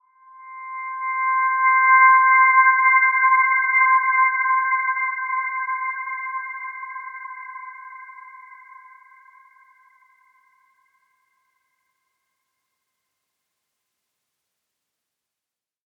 Dreamy-Fifths-C6-mf.wav